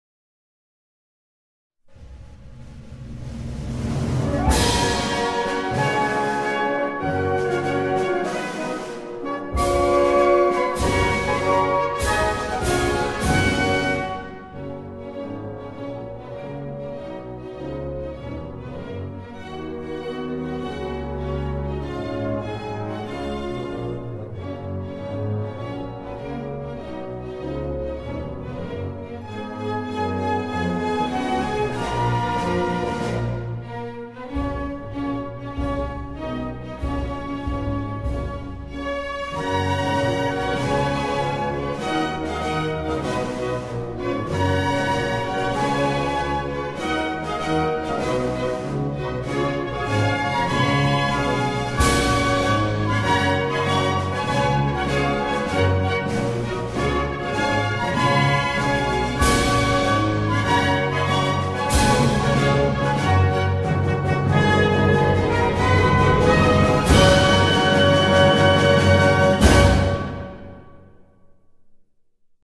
National Anthem
Guinea-Bissau_-_National_Anthem_1.mp3